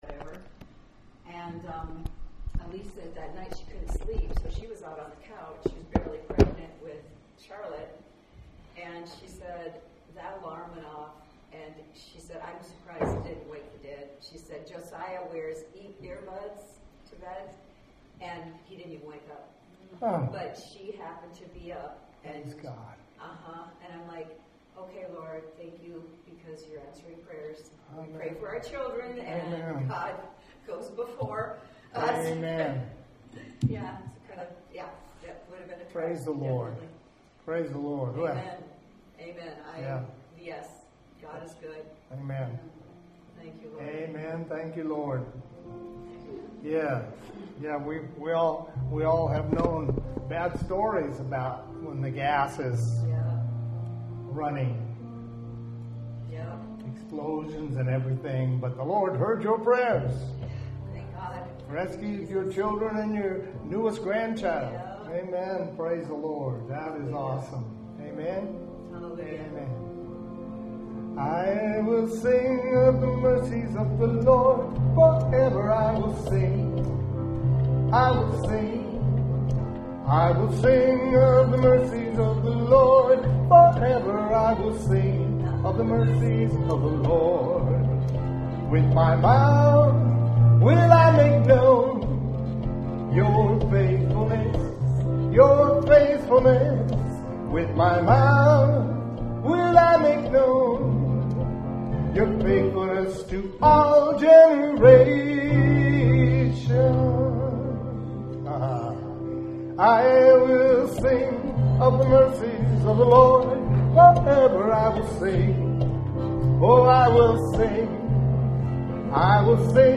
Here you’ll find a selection of audio recordings from Hosanna Restoration Church.
WORSHIP 76.mp3